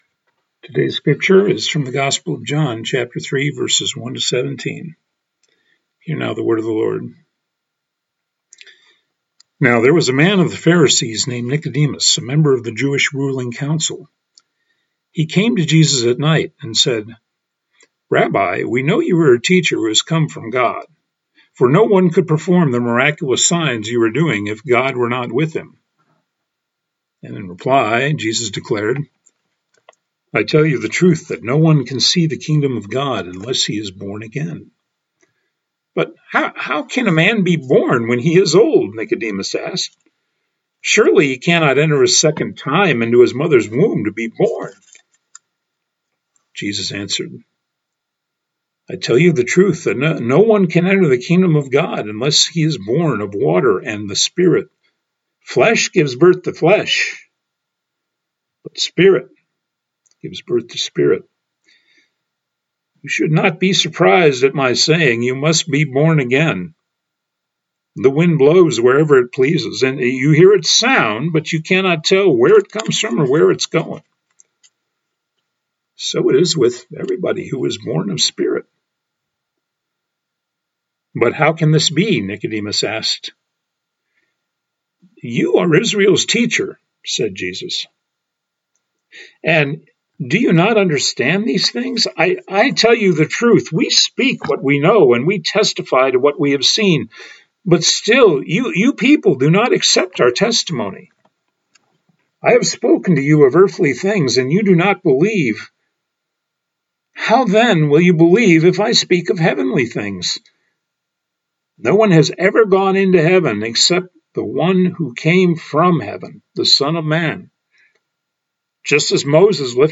Scripture